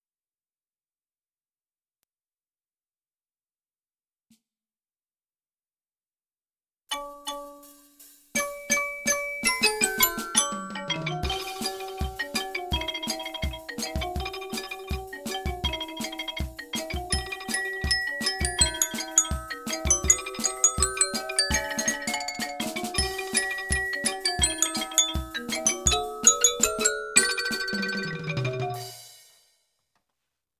Others sound terrible and even feature some wrong notes.